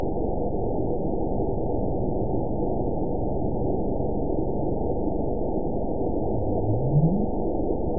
event 911835 date 03/09/22 time 22:17:50 GMT (3 years, 2 months ago) score 9.65 location TSS-AB03 detected by nrw target species NRW annotations +NRW Spectrogram: Frequency (kHz) vs. Time (s) audio not available .wav